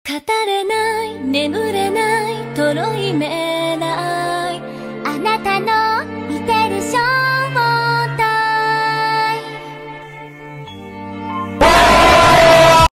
Category: Anime Soundboard